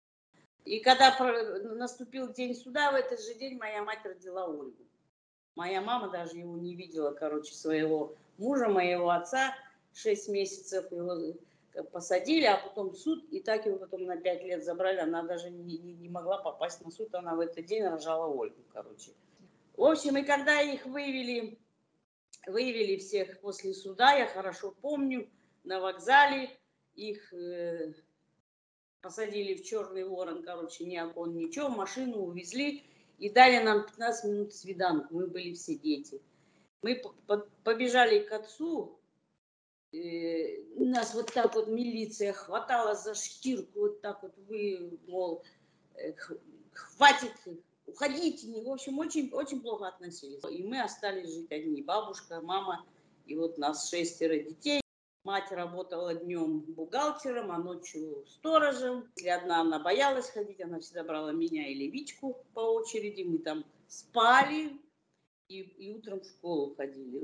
Interviewsequenz Inhaftierung